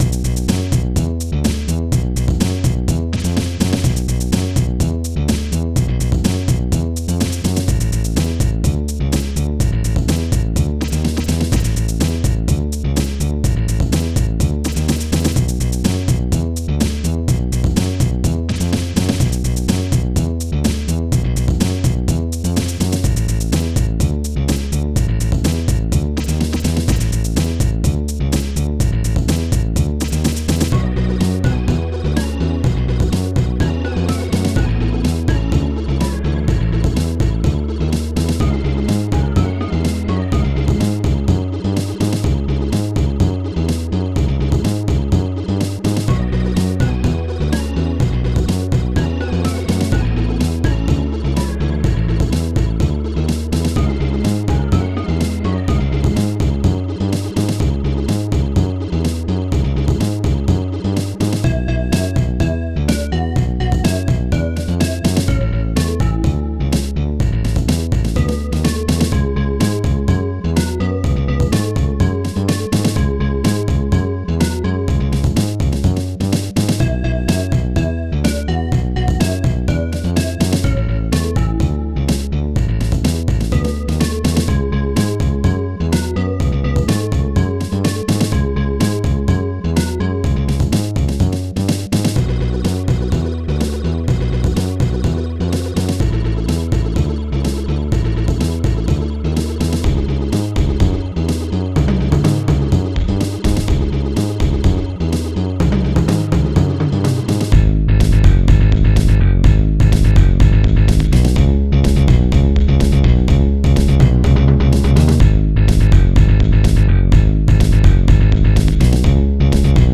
SoundTracker Module
2 channels
st-01:bassdrum3 st-01:slapbass st-02:pinvoice st-01:snare5 st-02:peck st-02:perc-bongo st-01:shaker st-02:speowl st-02:longslap